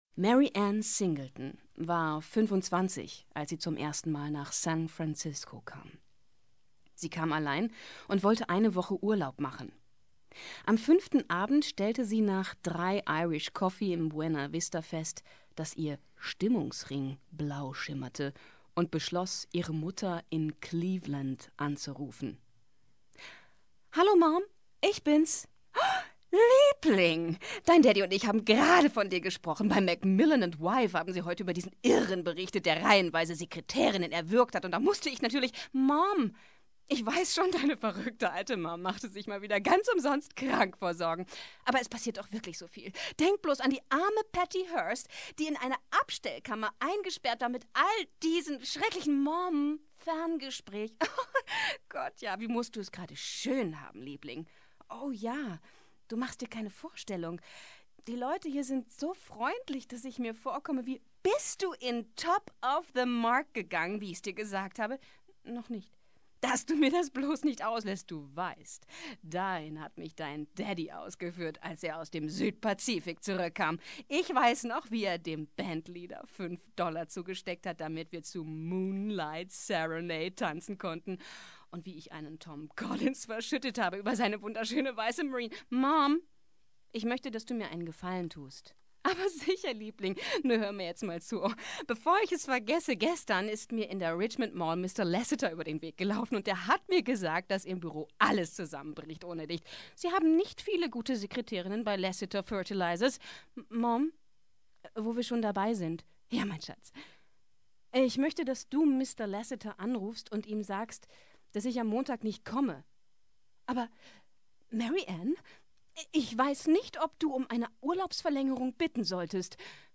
Lesung.mp3